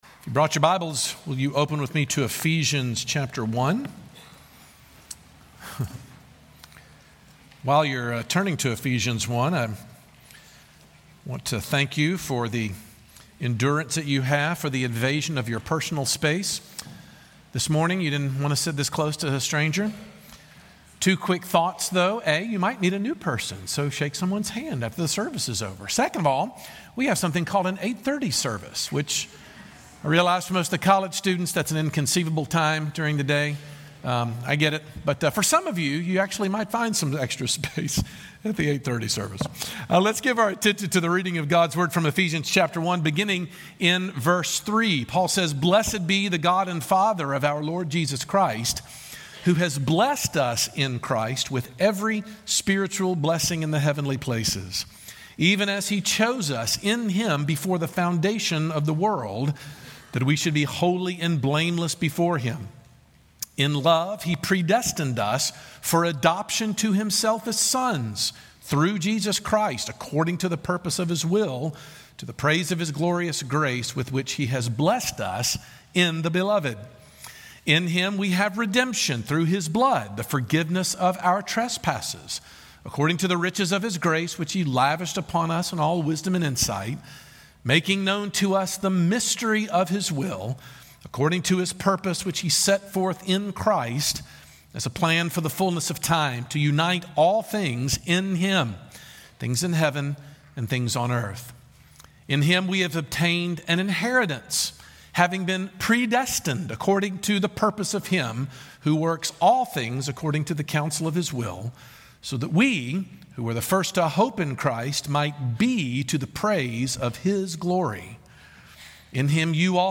Paul sits down to contemplate the blessings God's people have in Christ and what follows is a litany of keywords about the Christian's spiritual status in one long run-on sentence of pure joy. Sermon